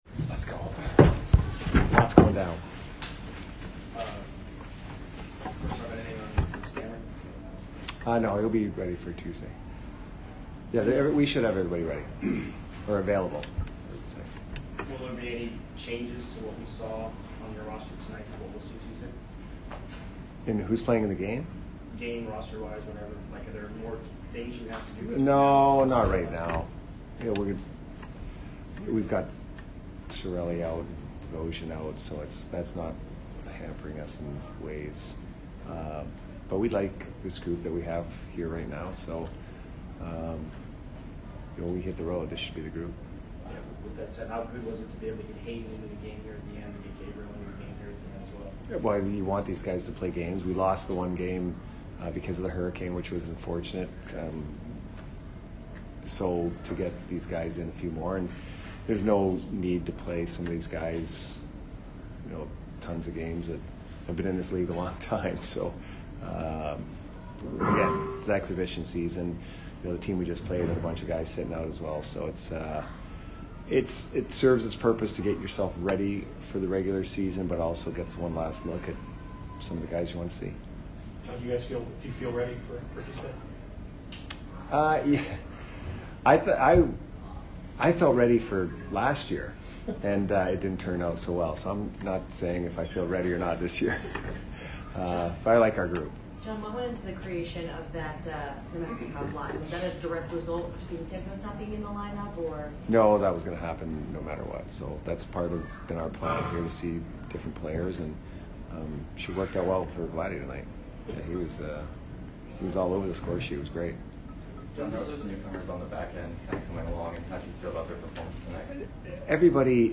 Head Coach Jon Cooper Postgame 10/8/22 vs. FLA